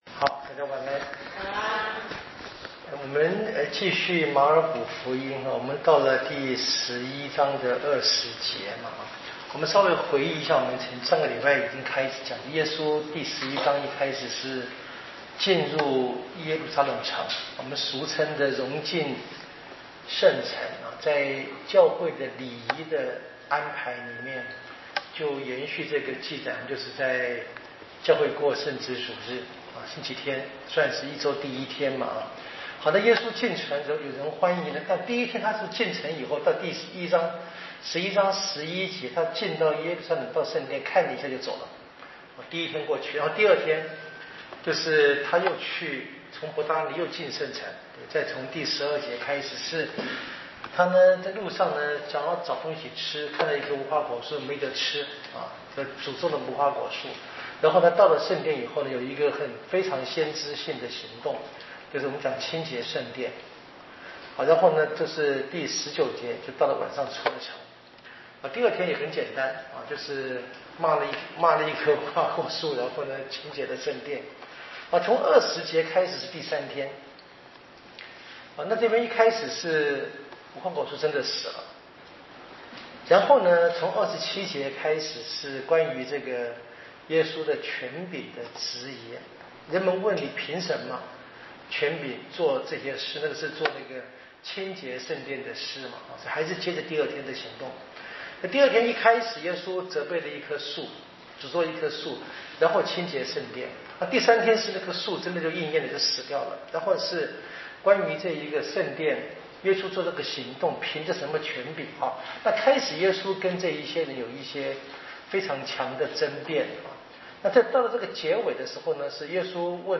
圣经讲座】《马尔谷福音》